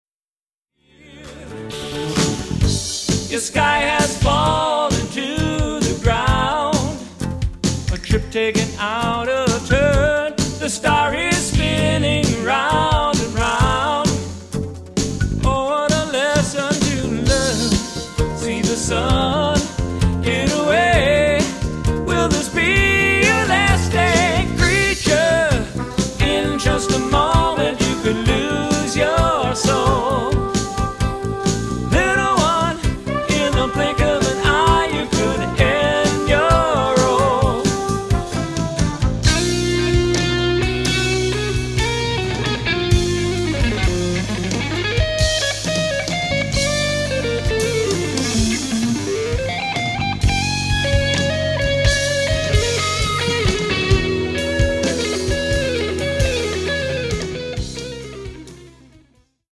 drums
guitar (lead solo)
bass, french horn, keyboards, percussion, trumpet, vocals